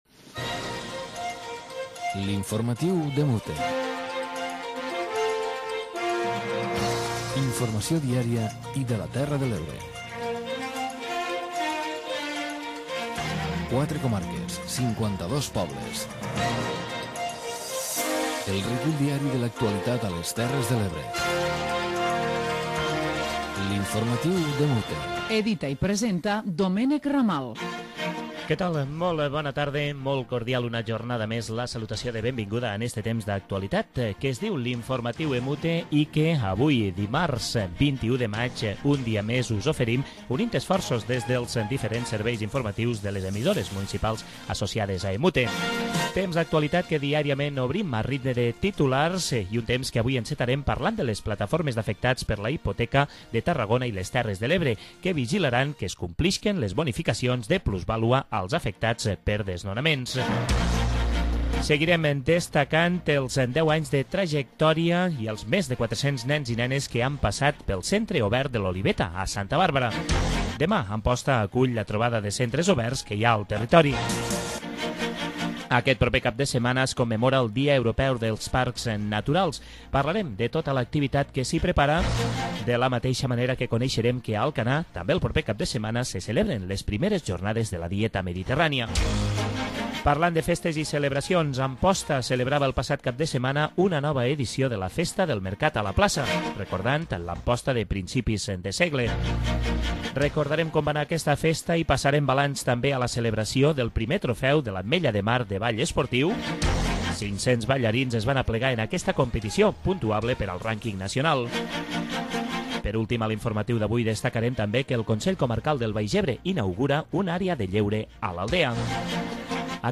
Informatiu comarcal diari de les emissores municipals de les Terres de l'Ebre.